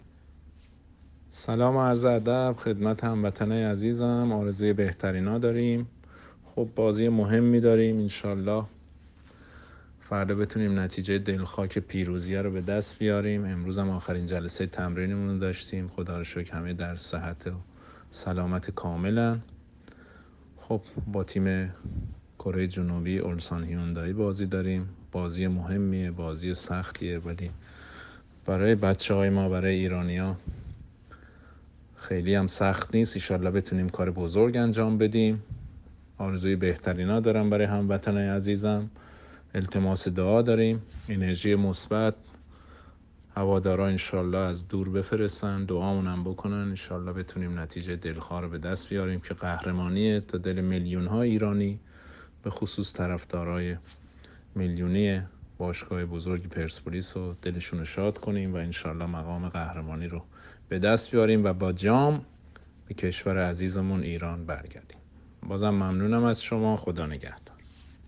پیام صوتی افشین پیروانی به هواداران پرسپولیس / اختصاصی طرفداری